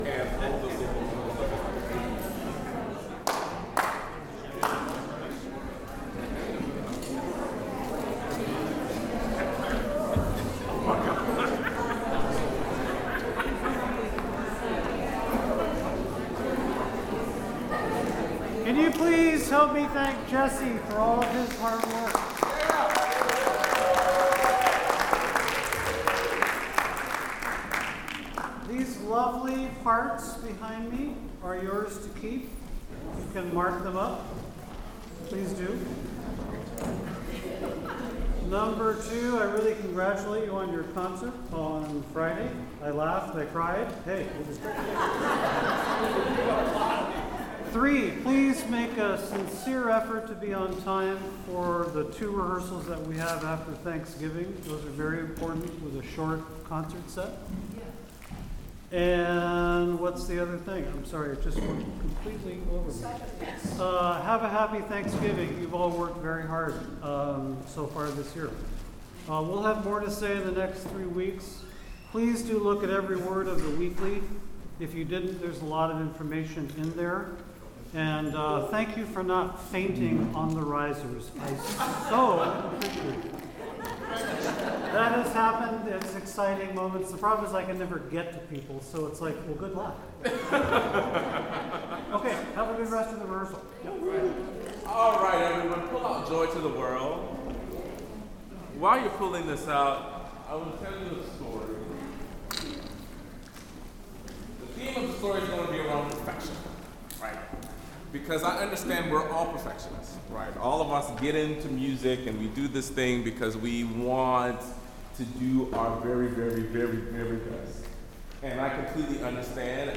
OSC Rehearsal, Wednesday, November 19, 2025
Work on Bach Magnificat